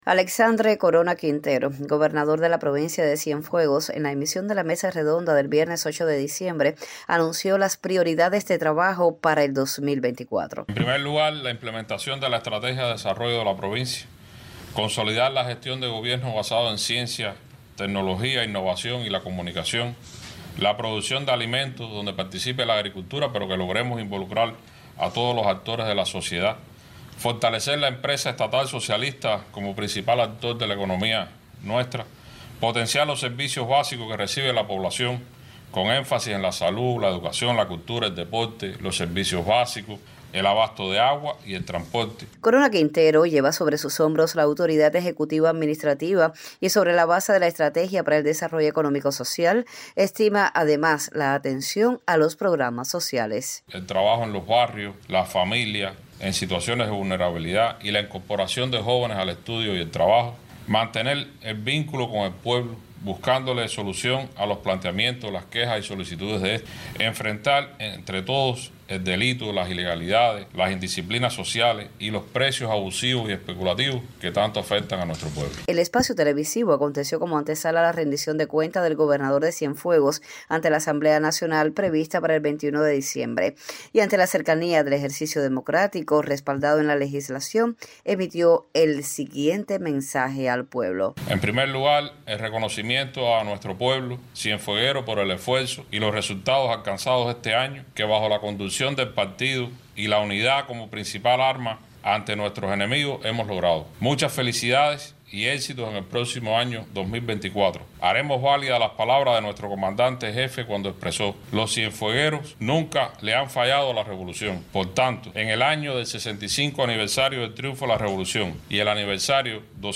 Alexandre Corona Quintero, gobernador de la provincia de Cienfuegos y máxima autoridad ejecutiva-administrativa de la provincia, en emisión de Mesa Redonda anunció las prioridades de trabajo para el 2024.